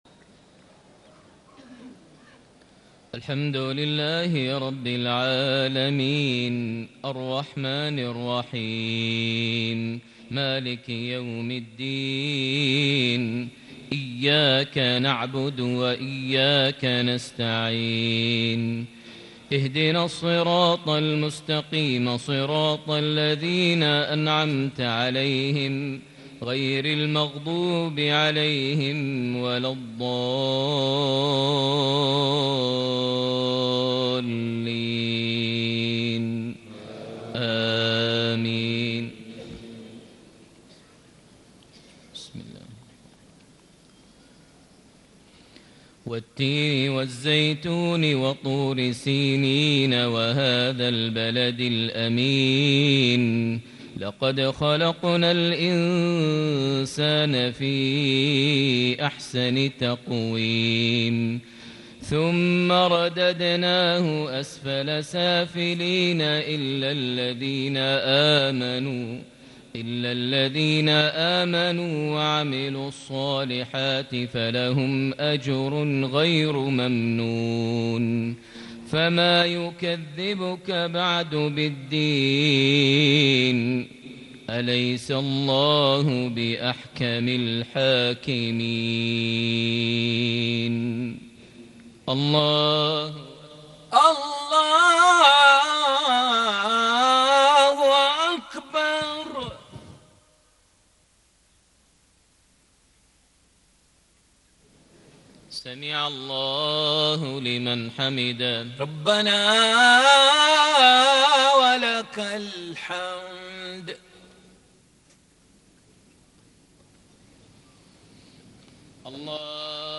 صلاة العشاء ١٢ ذي الحجة ١٤٣٨هـ سورتي التين / قريش > 1438 هـ > الفروض - تلاوات ماهر المعيقلي